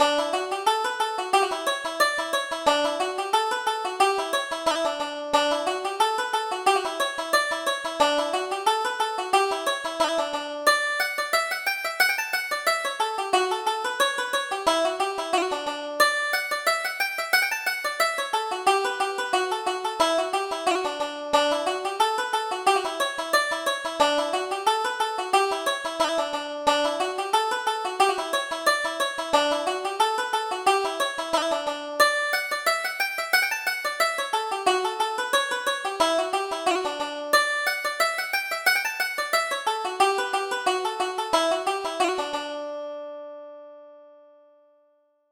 Reel: The Silver Tip